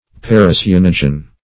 Search Result for " paracyanogen" : The Collaborative International Dictionary of English v.0.48: Paracyanogen \Par`a*cy*an"o*gen\, n. [Pref. para- + cyanogen.]
paracyanogen.mp3